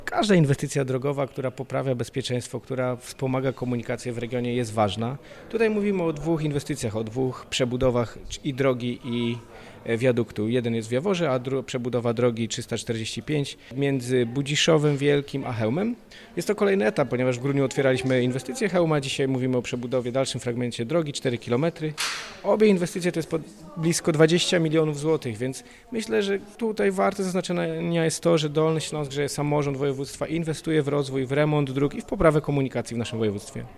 – Spotykamy się dziś, aby podpisać umowy na 20 milionów złotych, które przeznaczymy na Dolnośląskie Drogi, na poprawę bezpieczeństwa, na usprawnienie komunikacji na Dolnym Śląsku – powiedział Michał Rado, wicemarszałek województwa dolnośląskiego.